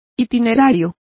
Complete with pronunciation of the translation of itinerary.